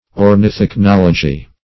Search Result for " ornithichnology" : The Collaborative International Dictionary of English v.0.48: Ornithichnology \Or`nith*ich*nol"o*gy\, n. [Ornitho- + ichnology.]